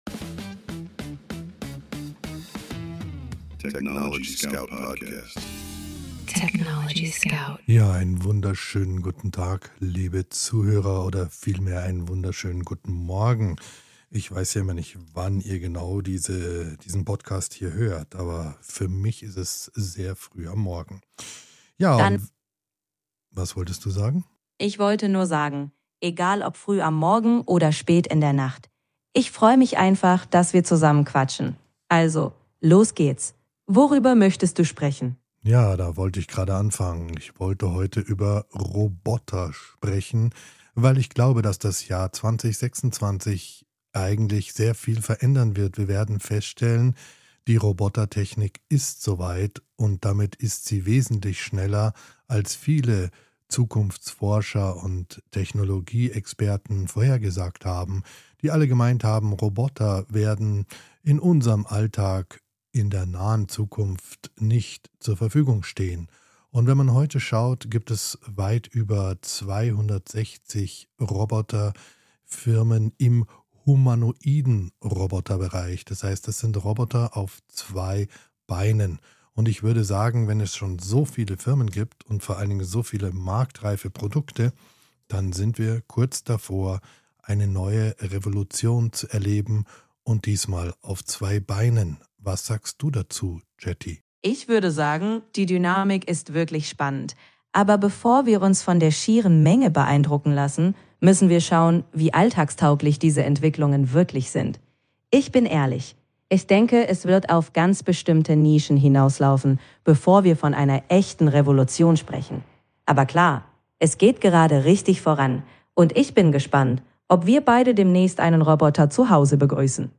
Mensch und KI sprechen miteinander – nicht gegeneinander.